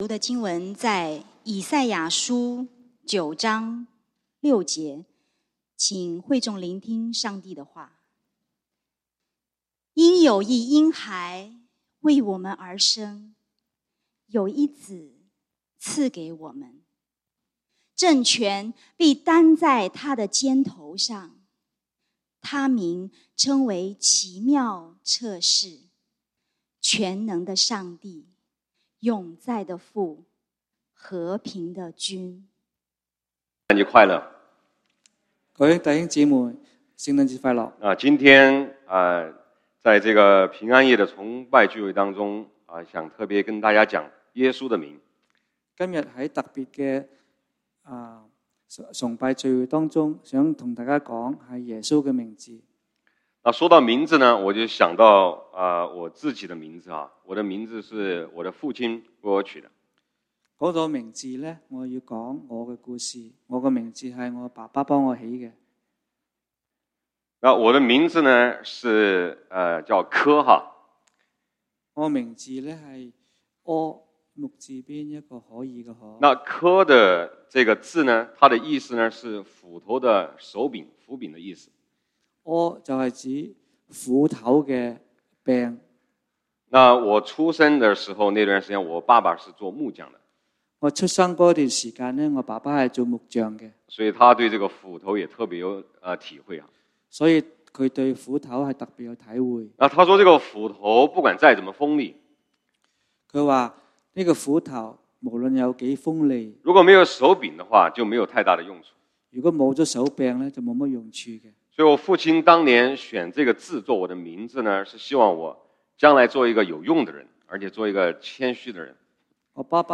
平安夜崇拜：「祂的名」(經文：以賽亞書 9:6) | External Website | External Website